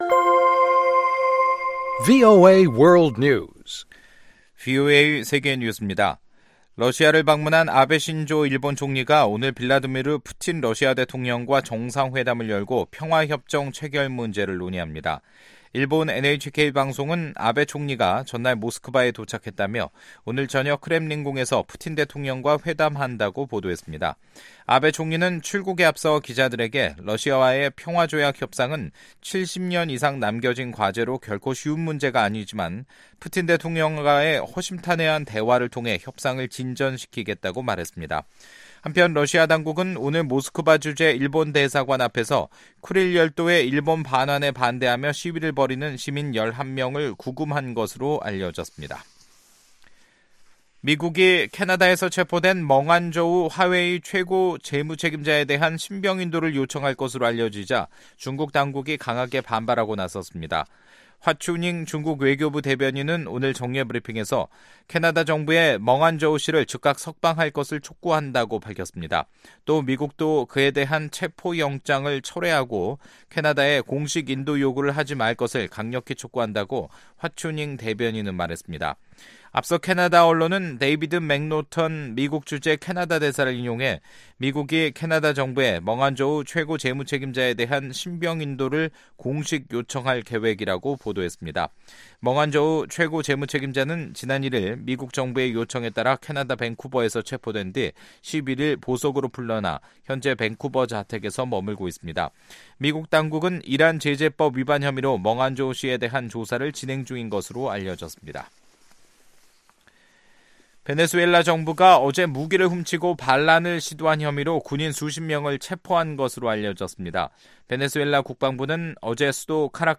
VOA 한국어 간판 뉴스 프로그램 '뉴스 투데이', 2019년 1월 22일 2부 방송입니다. 취임 2주년을 맞이한 트럼프 행정부가 북한의 무기 실험 중단과 미-북 정상회담 개최 등을 긍정적인 성과로 자평했습니다. 유엔인권사무소는 납북된 한국인 피해자가 가족들의 진상 규명과 생사 확인 노력을 지원하고 있다고 밝혔습니다.